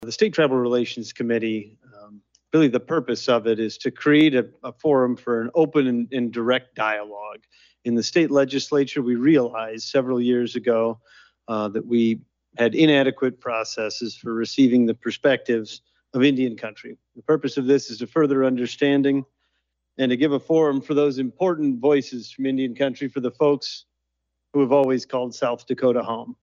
AGENCY VILLAGE, S.D.(HubCityRadio)- On Thursday, the first meeting of the Interim State Tribal Relations Committee was held at the Sisseton-Wahpeton Oyate Headquarters at Agency Village.
Chair of the committee, District 24 Representative Will Mortenson discuss the purpose of the committee.